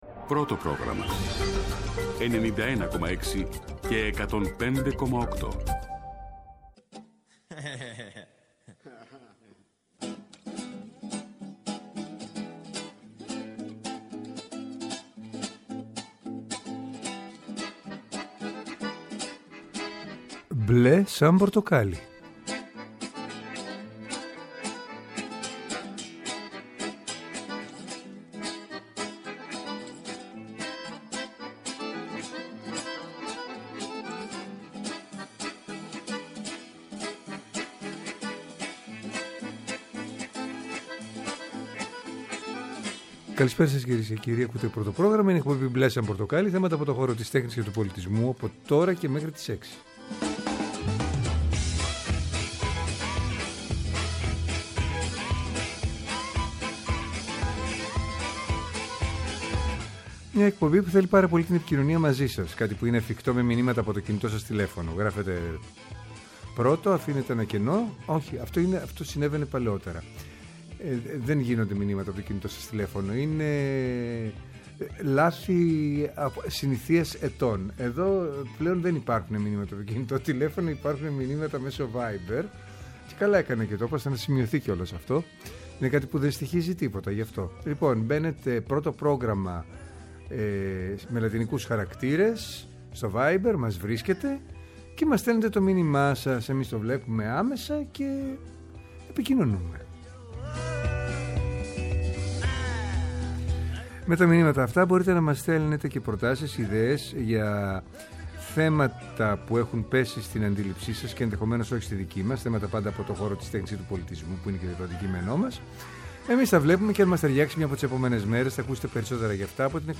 Καλεσμένοι μας τηλεφωνικά: